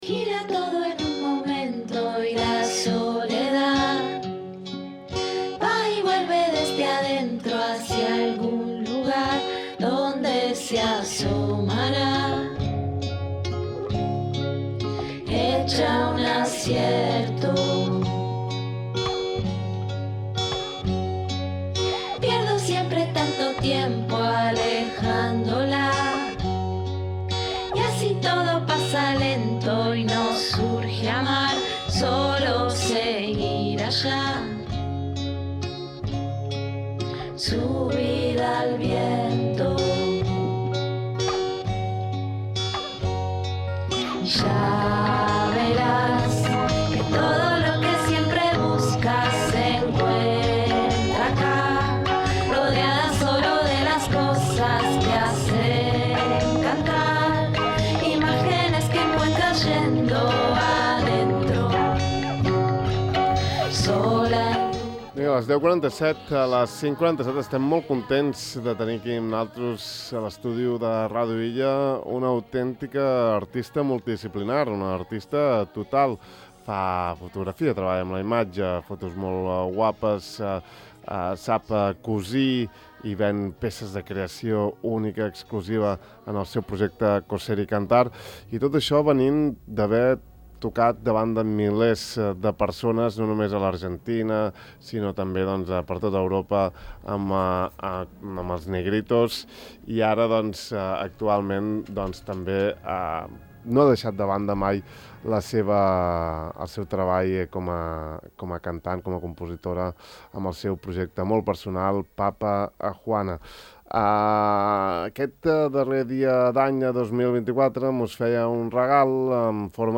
Avui l’hem tingut als estudis de Ràdio Illa, on hem conversat del procés creatiu, de les diverses influències, del bagatge amb els Negritos, i moltes altres coses més. Fins i tot, ens ha regalat una petita actuació en directe.